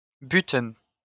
Butten (French pronunciation: [bytən]